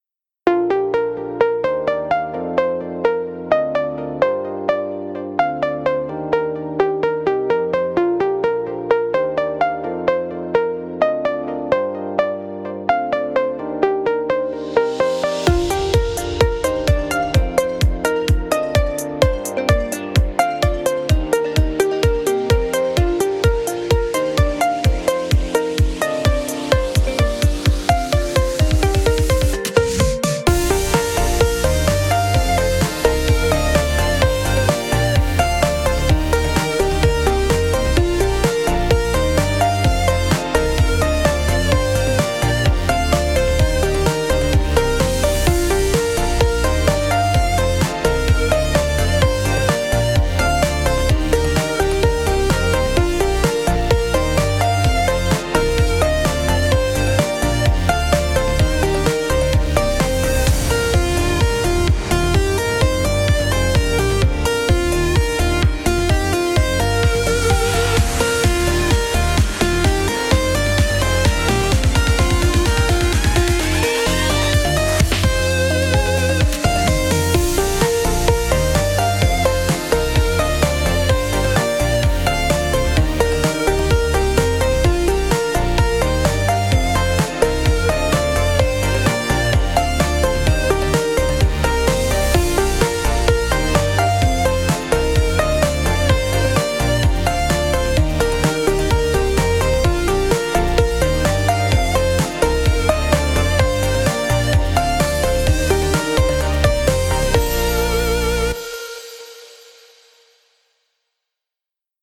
かわいい雰囲気のゆったりした明るいBGMです。